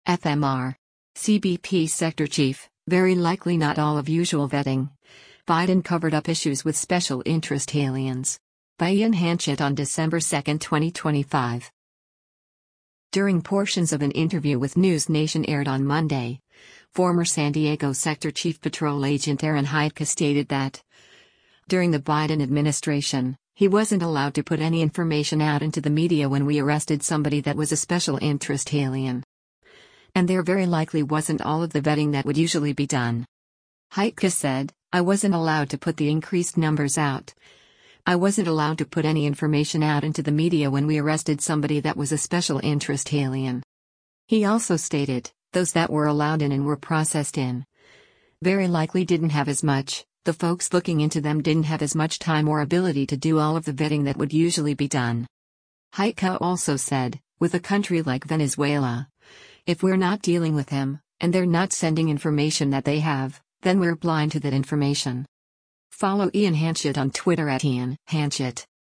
During portions of an interview with NewsNation aired on Monday, former San Diego Sector Chief Patrol Agent Aaron Heitke stated that, during the Biden administration, he “wasn’t allowed to put any information out into the media when we arrested somebody that was a special interest alien.”